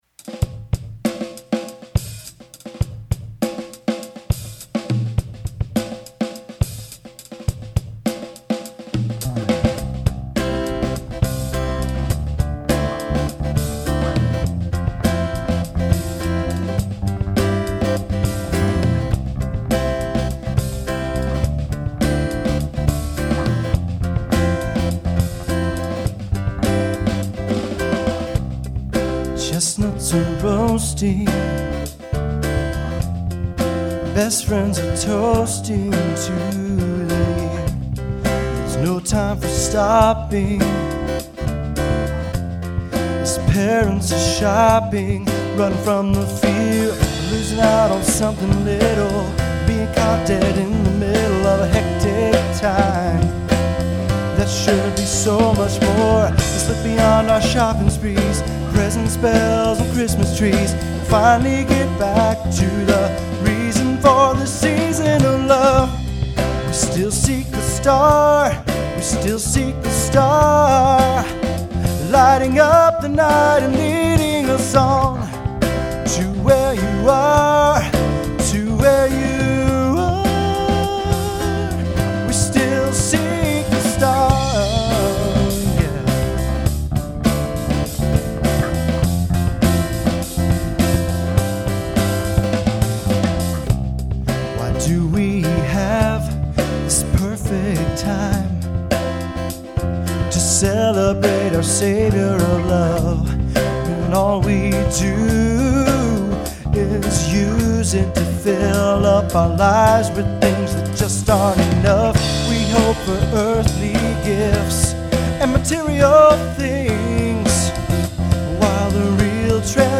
Drum & bass
Rock & Roll